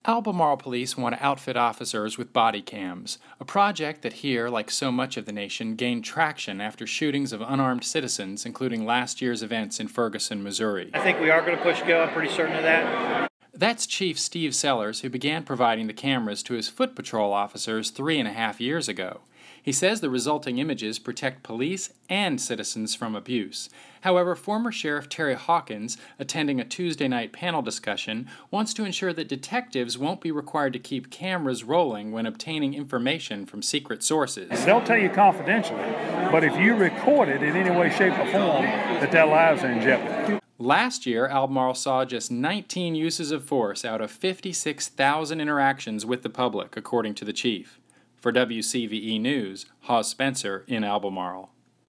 However, former Sheriff Terry Hawkins, attending a Tuesday night panel discussion, wants to ensure that detectives won't be required to keep c